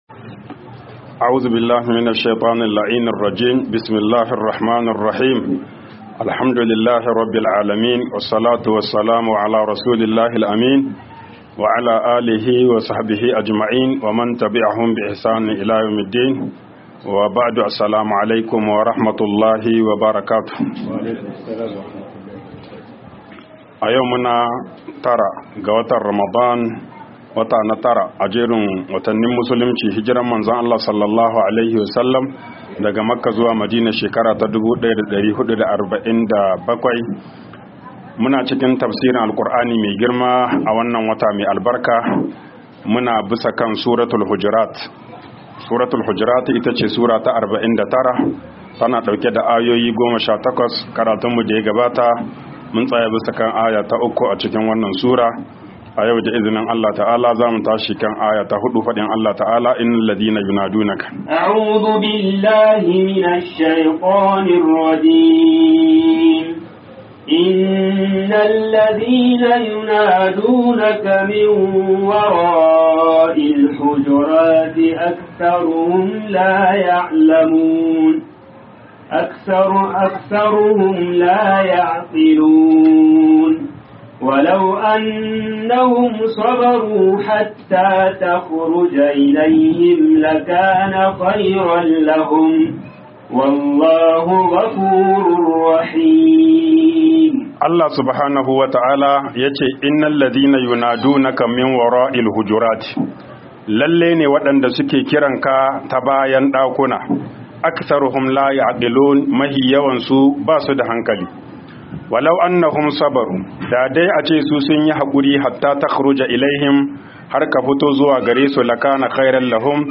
TAFSIR RAMADAN MASJID ALSHABAB 08